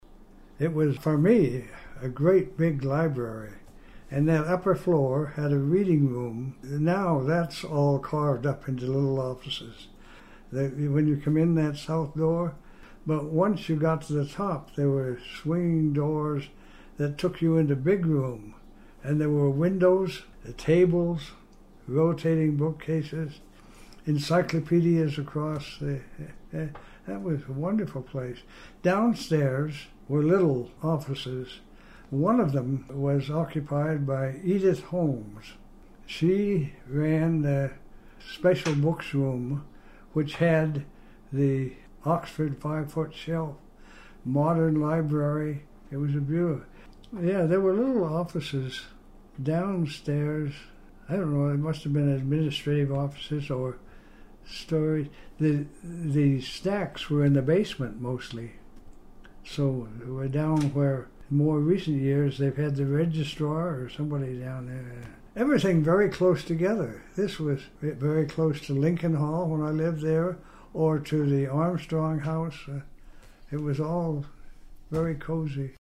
University of Nevada Oral History Program